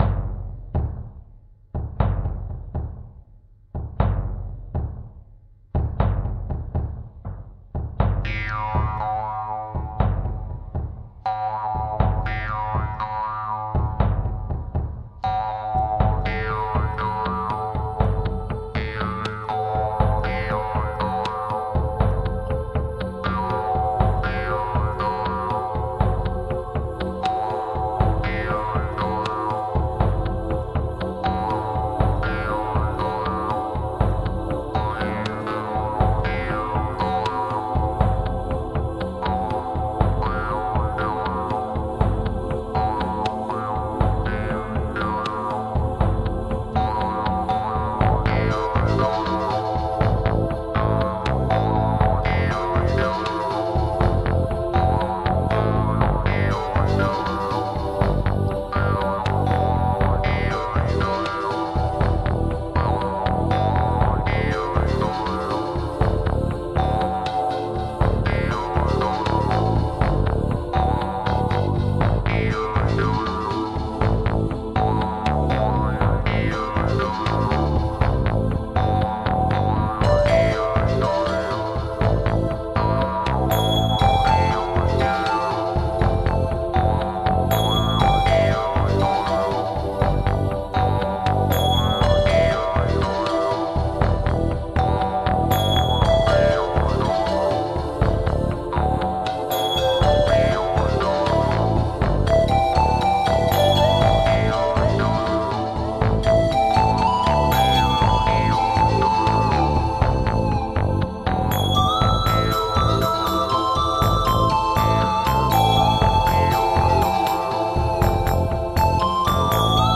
New age/world music for mankind from russia.
New Age meets World Music.
Tagged as: New Age, Inspirational, Chillout